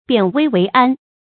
变危为安 biàn wēi wéi ān 成语解释 变危急为平安。